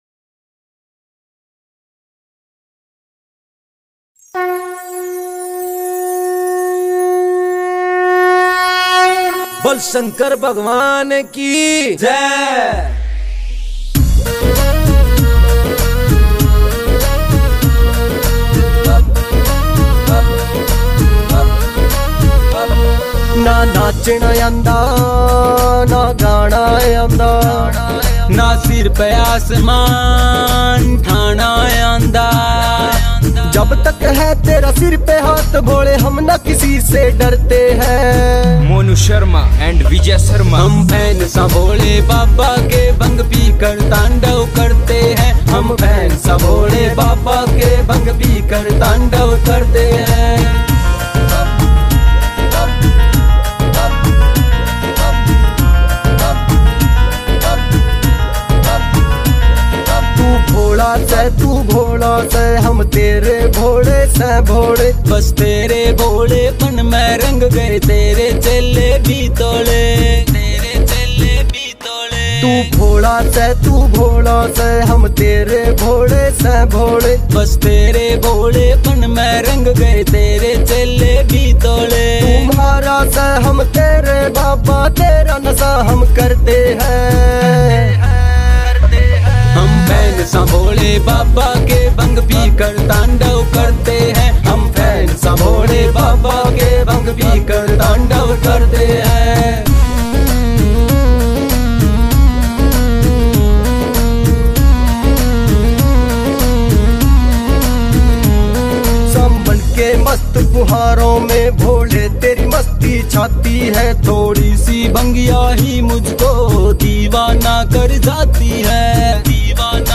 Bhakti Songs